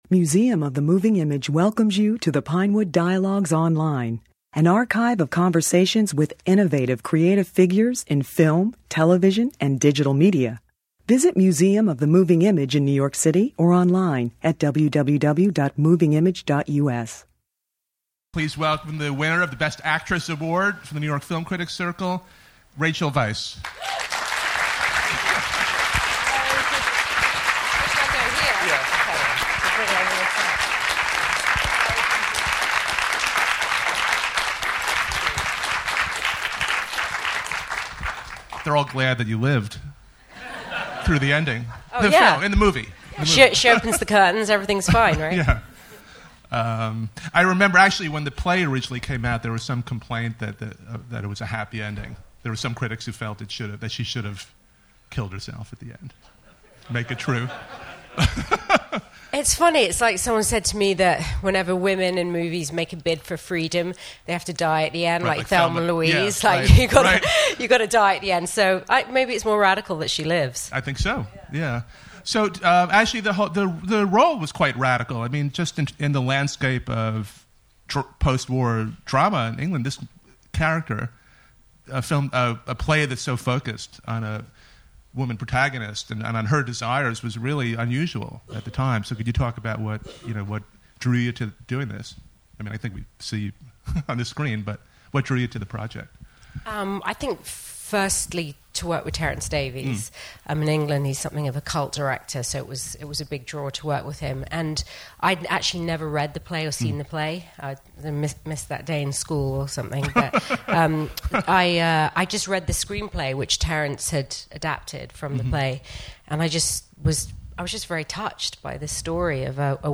Weisz brings to an unmatched luminosity, magnetism, and emotional rawness to her performance, which she discussed after a screening of the film at the Museum of the Moving Image.